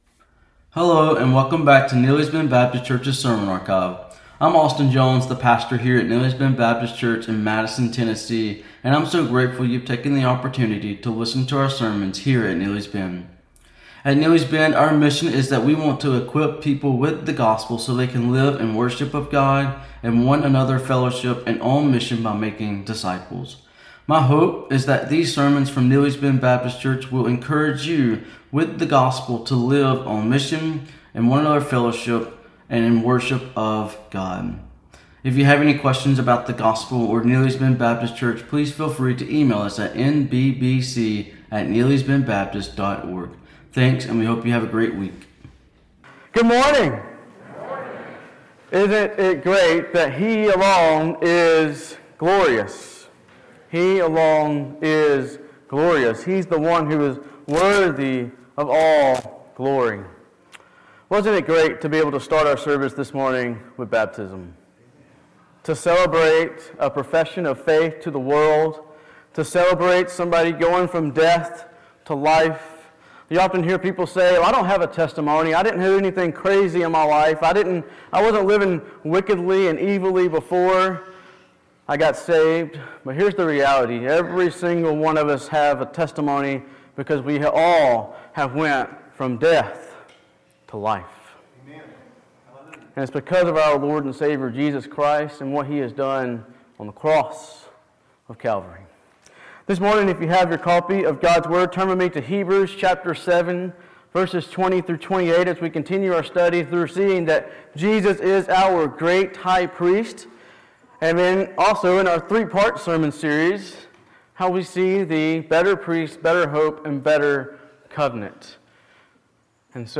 Sermons | Neely's Bend Baptist Church